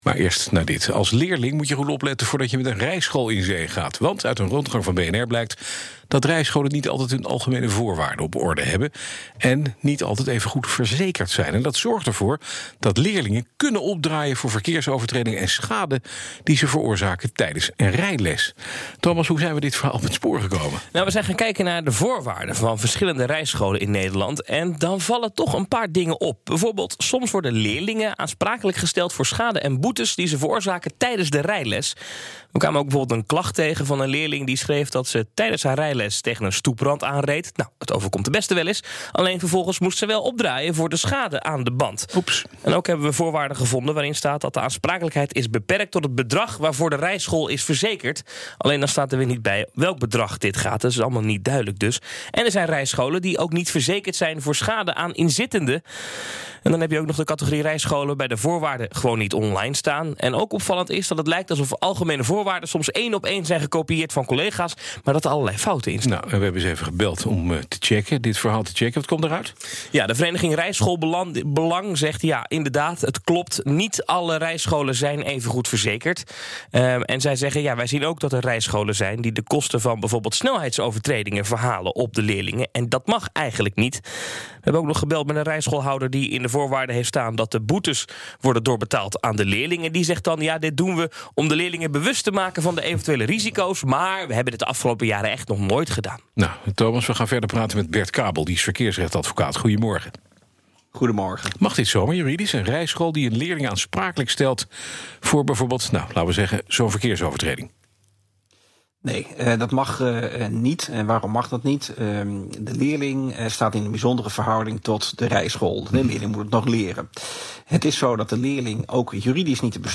Rijscholen-hebben-algemene-voorwaarden-en-verzekering-niet-altijd-op-orde-BNR-Nieuwsradio.mp3